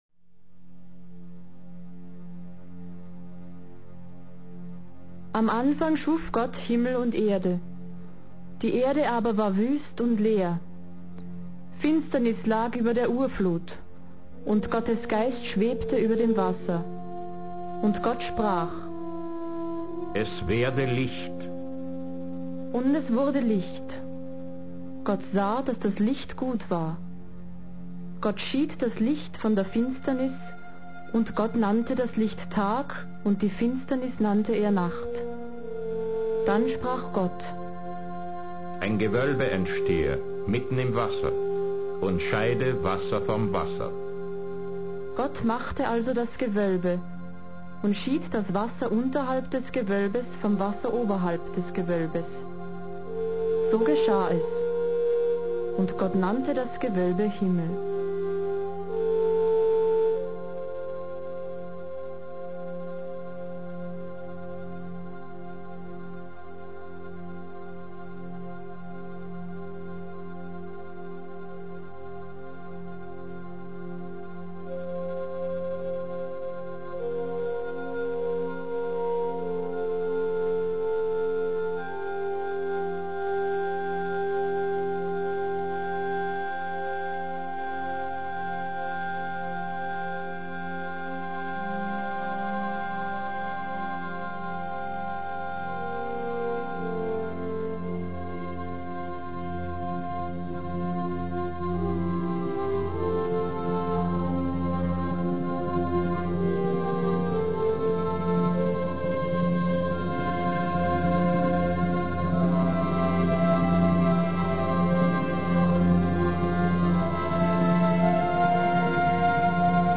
Gattung: Vertonung von Bibelversen
Besetzung: Blasorchester